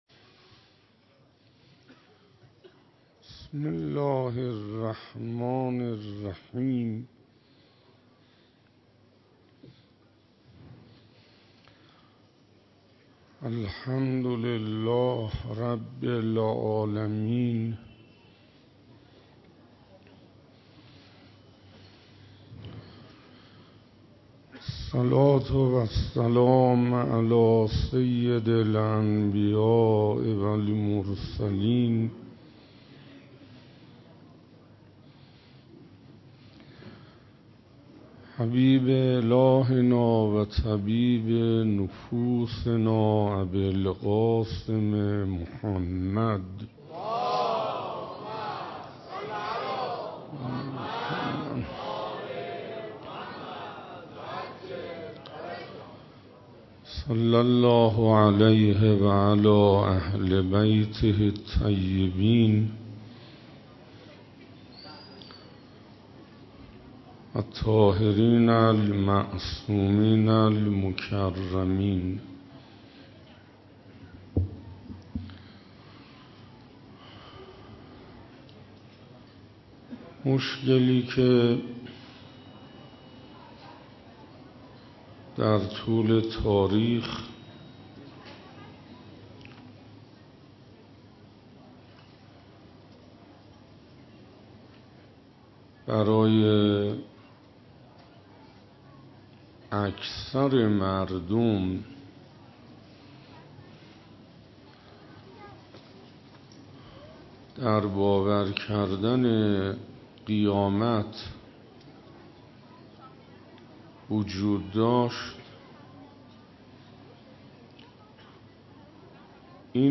دهه دوم صفر - شب سوم - قیامت - حسینیه نیاوران